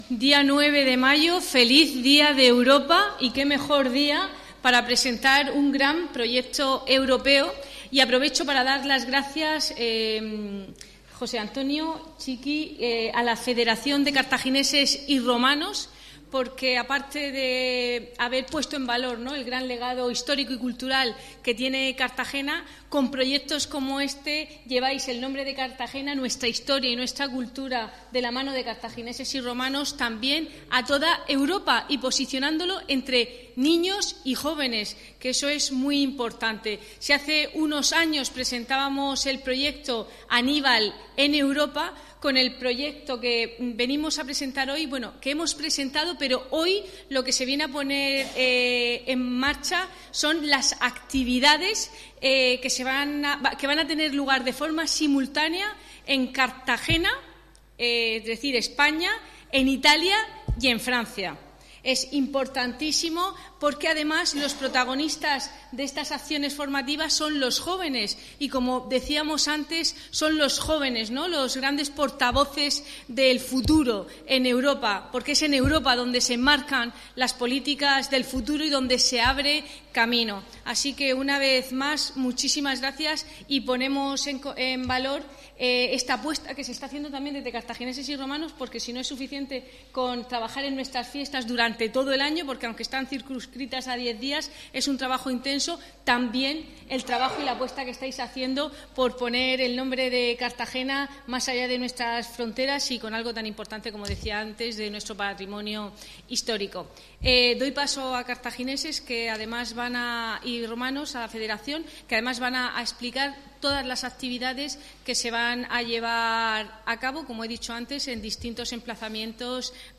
Enlace a Presentación del cartel de actividades del proyecto Aníbal por Europa